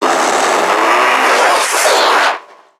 NPC_Creatures_Vocalisations_Infected [20].wav